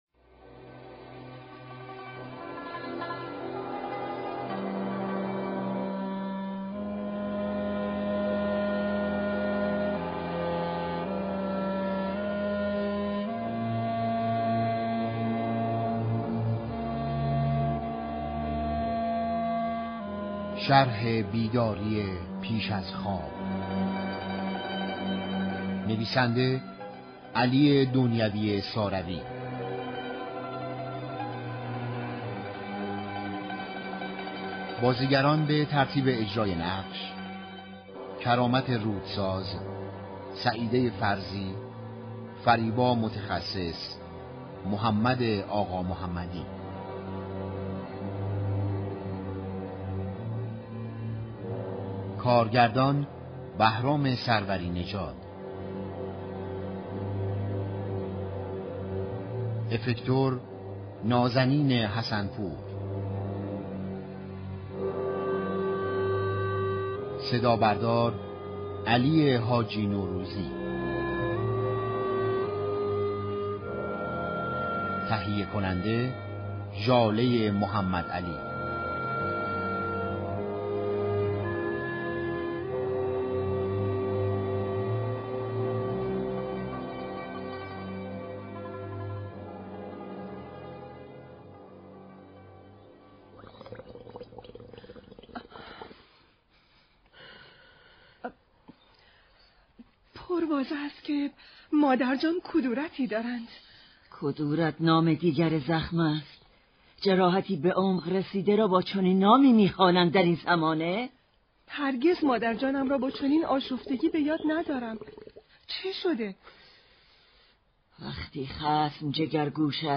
پخش یك نمایش رادیویی ویژه ی بزرگداشت امیركبیر از رادیو نمایش